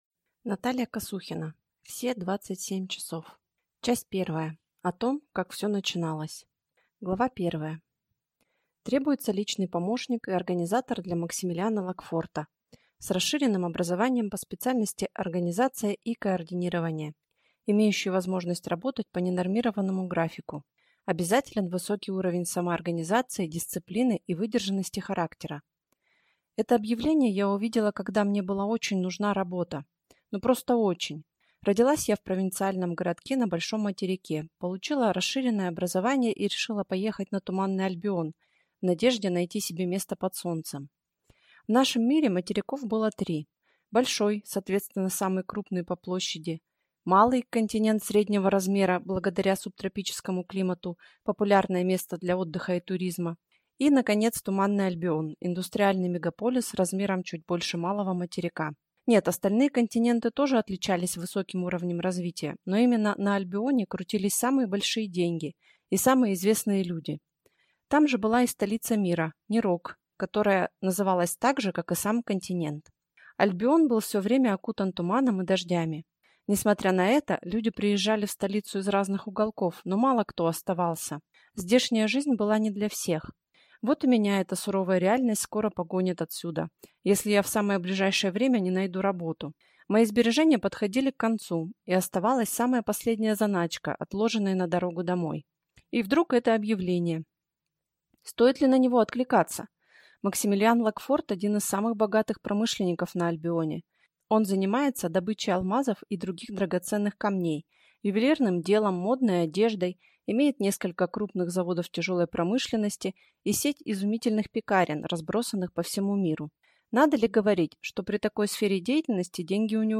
Аудиокнига Все двадцать семь часов!